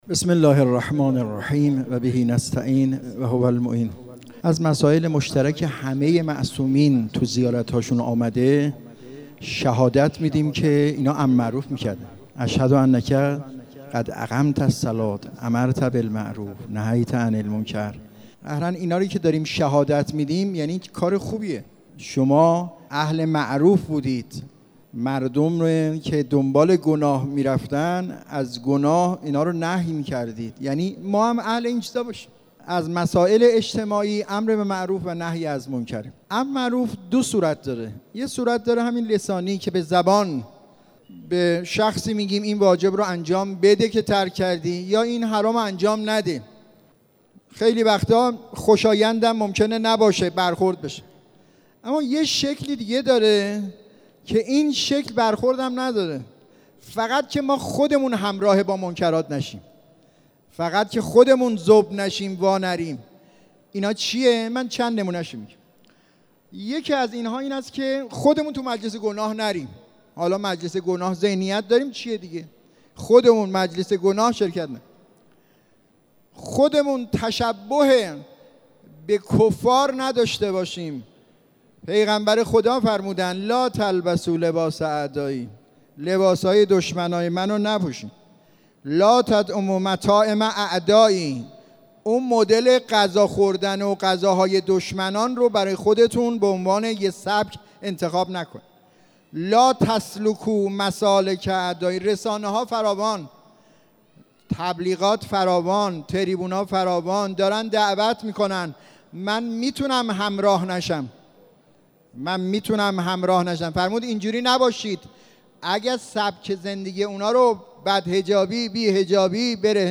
صورتهای امر به معروف و نهی از منکر - حرم حضرت فاطمه معصومه (س) با ترافیک رایگان